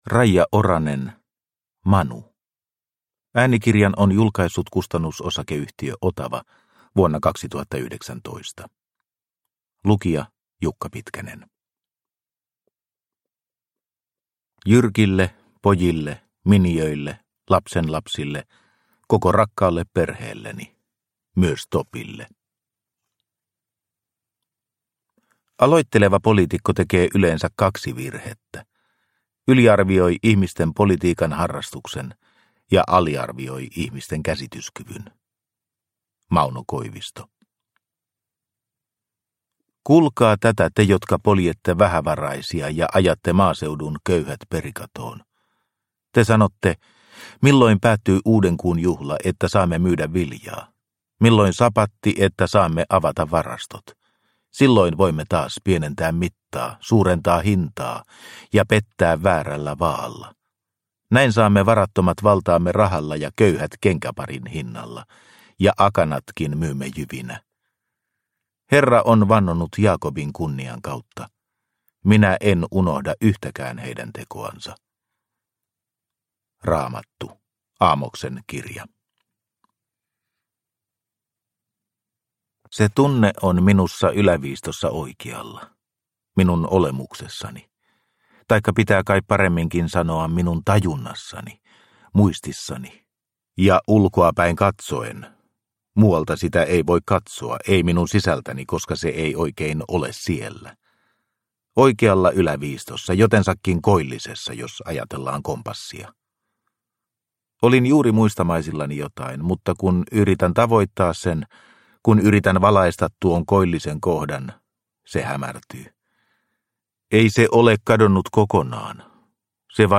Manu – Ljudbok – Laddas ner